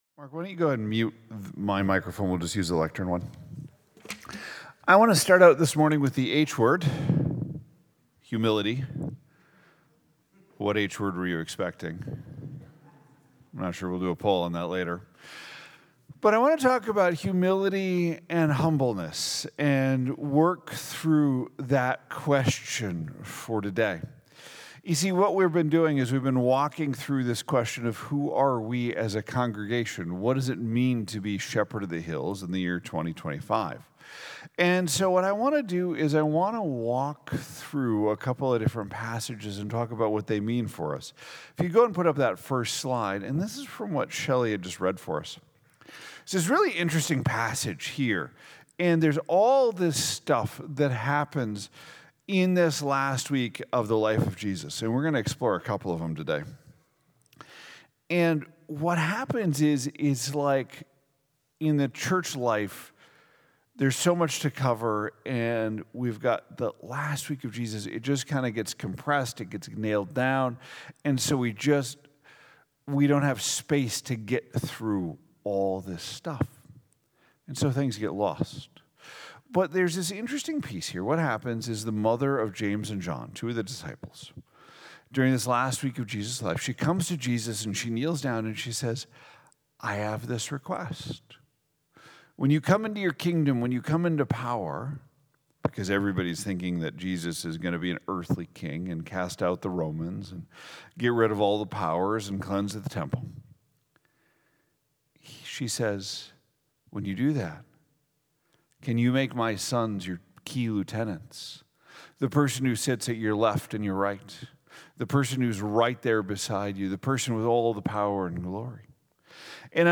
2025 Who Are We service welcome Sunday Morning Sunday